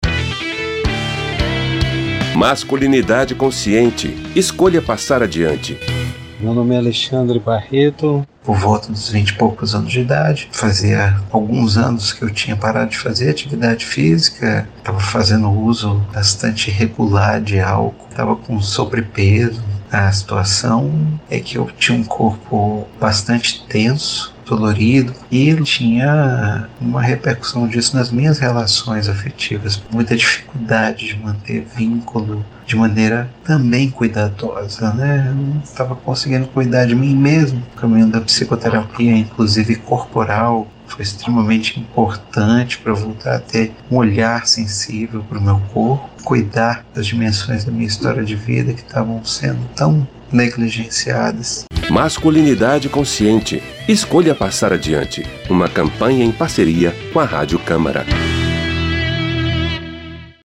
Em 13 depoimentos em áudio e cinco em vídeo com histórias reais de homens que romperam com medos e crenças a respeito da masculinidade, a Rádio Câmara lança a Campanha Masculinidade Consciente – escolha passar adiante.